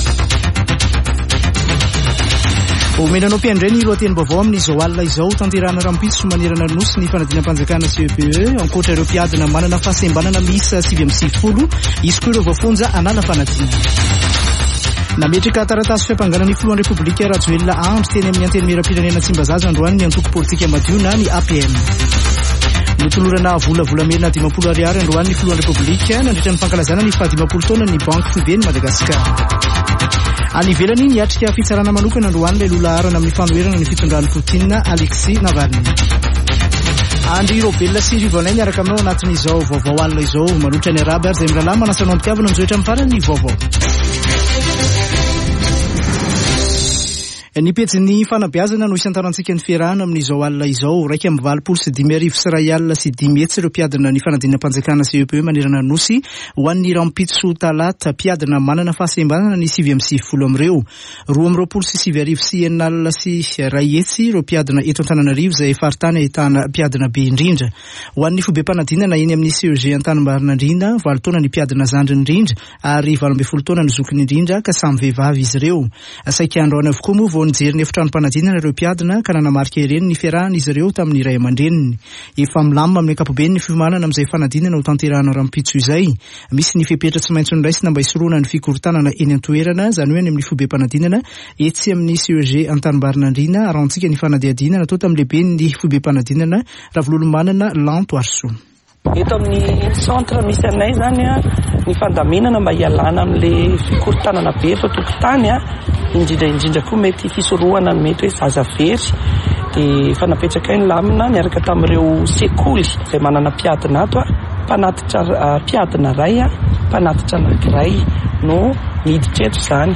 [Vaovao hariva] Alatsinainy 19 jona 2023